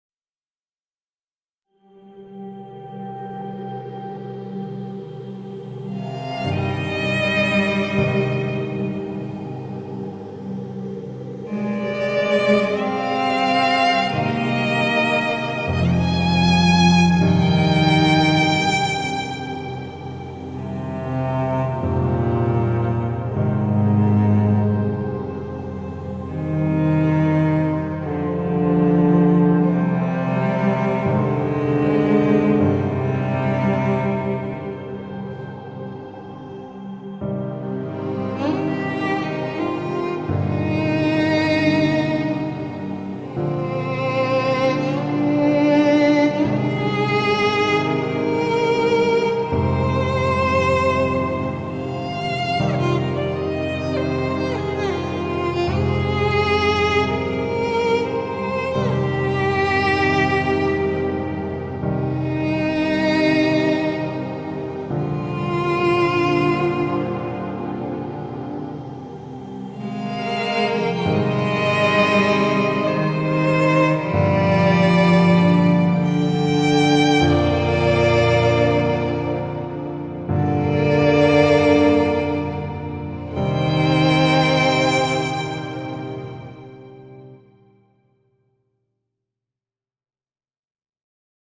tema dizi müziği